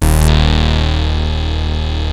OSCAR D#2 2.wav